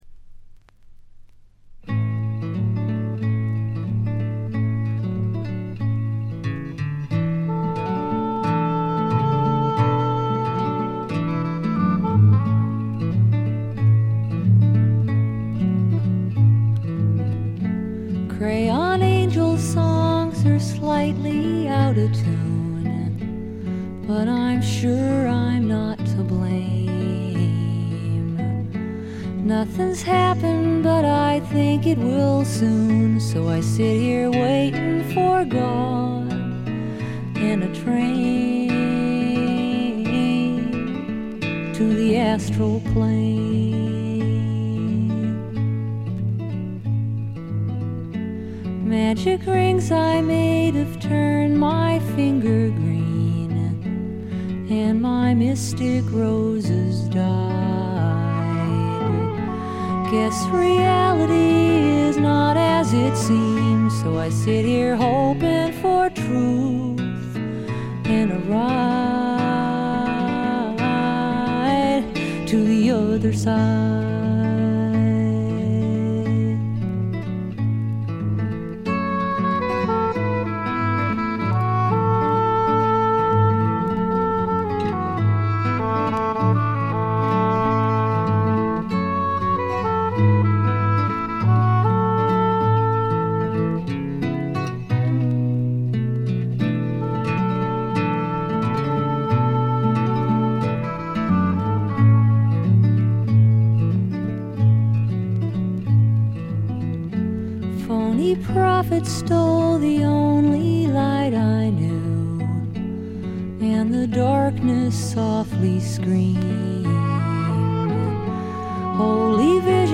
70年代を代表する女性シンガー・ソングライターかつアシッドフォーク作品の金字塔であります。
試聴曲は現品からの取り込み音源です。